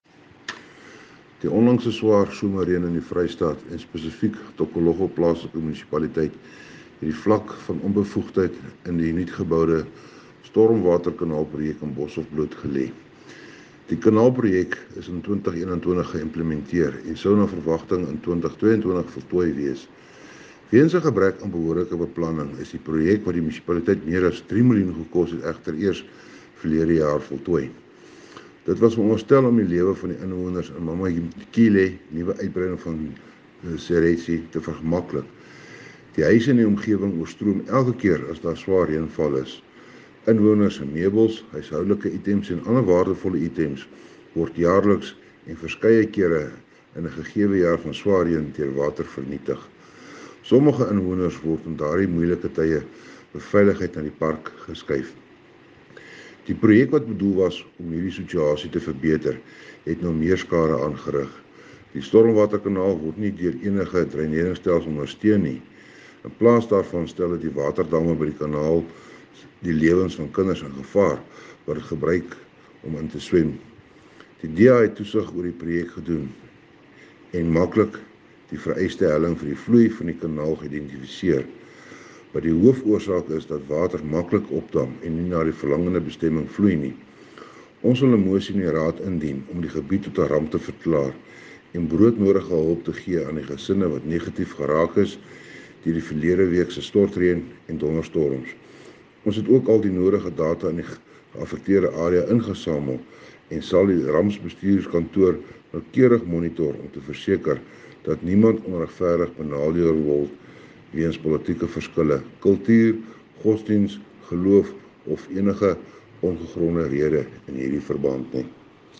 Afrikaans by Cllr Johann Steenkamp.